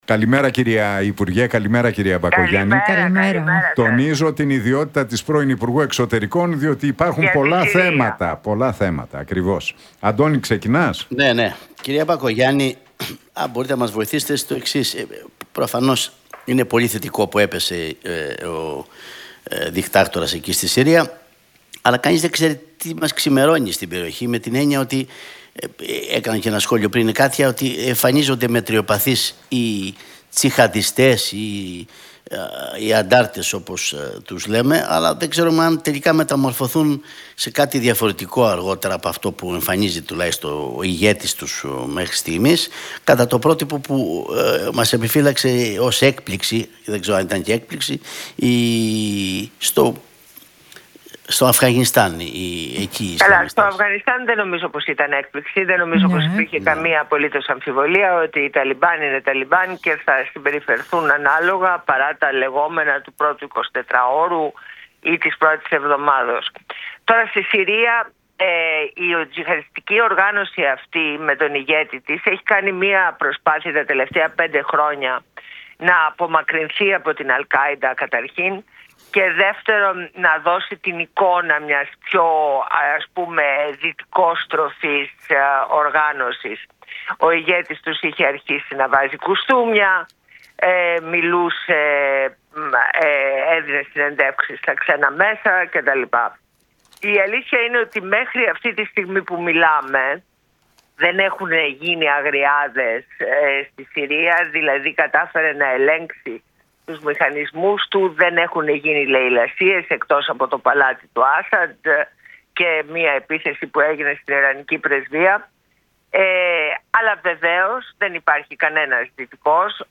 Μπακογιάννη στον Realfm 97,8: Ο Πρόεδρος της Δημοκρατίας δεν πρέπει να προέρχεται ούτε από την κεντροδεξιά ούτε από την κεντροαριστερά
στην συχνότητα του Realfm 97,8